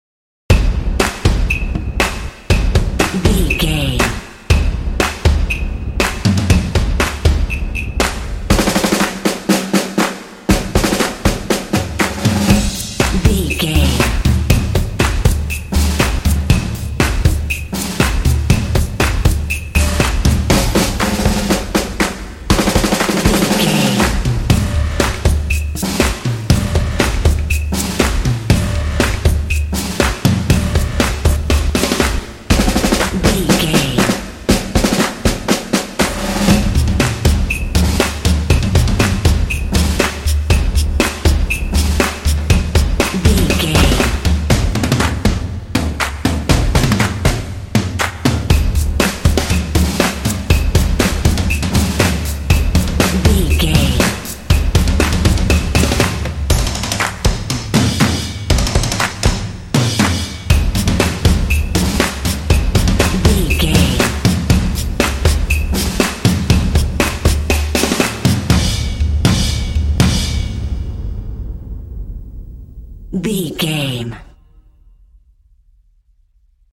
Epic / Action
Atonal
confident
percussion
drumline
drums